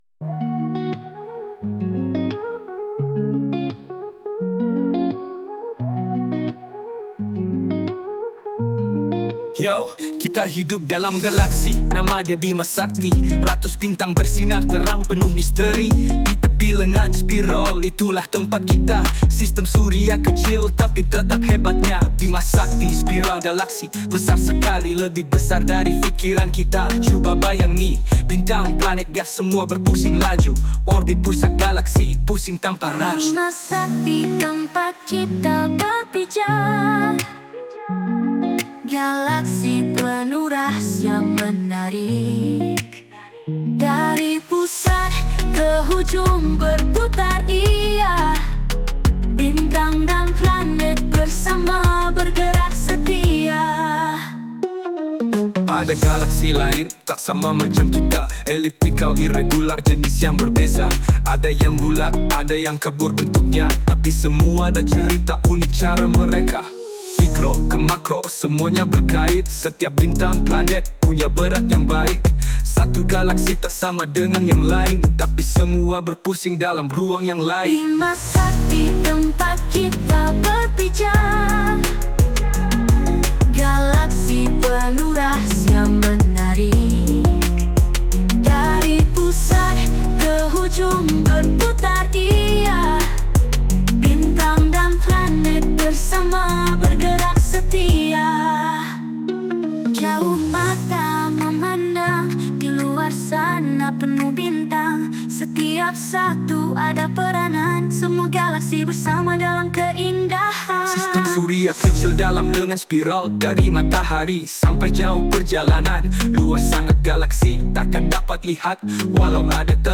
Berikut adalah lirik lagu bertemakan Unit 11: Galaksi dengan rentak RAP dan R&B yang merangkumi semua maklumat penting yang murid perlu pelajari.